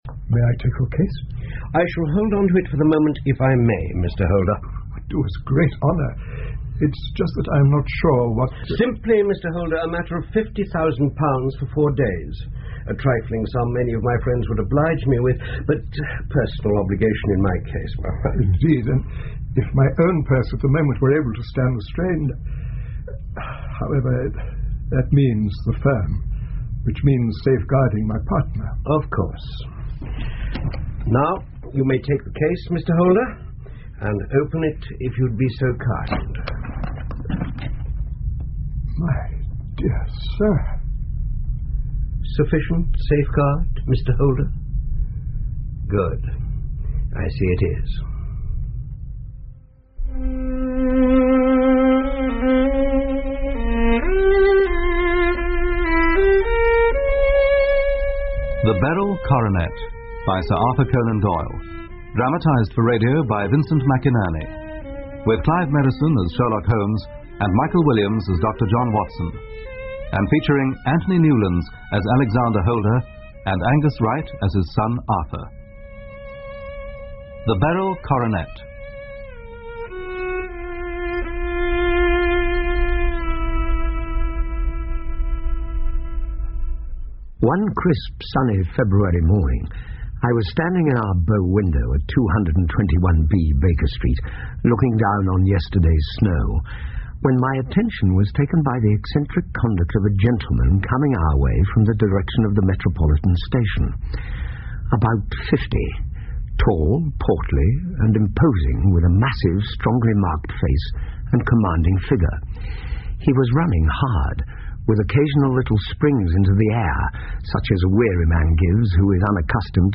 福尔摩斯广播剧 The Beryl Coronet 1 听力文件下载—在线英语听力室